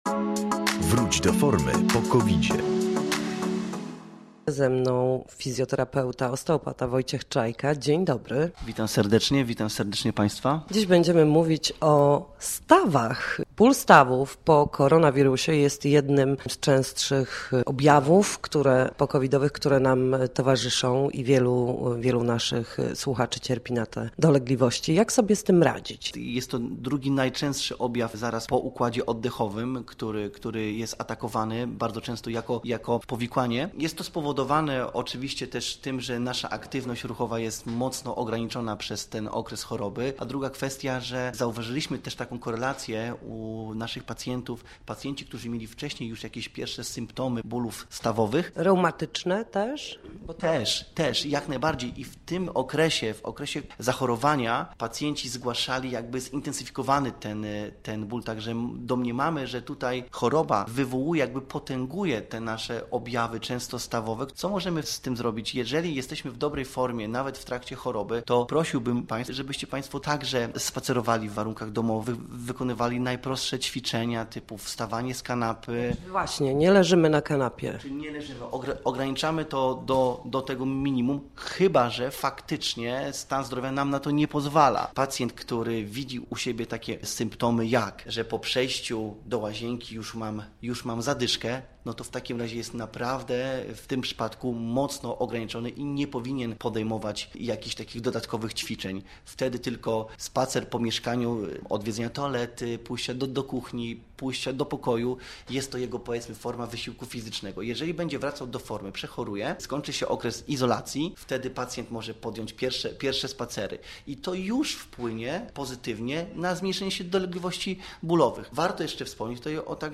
Co tydzień w poniedziałek o godz. 7:20 na antenie Studia Słupsk można posłuchać gości, w tym lekarzy czy fizjoterapeutów, którzy odpowiadają na jedno pytanie dotyczące najczęstszych dolegliwości po chorobie.